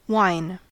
wine-us.mp3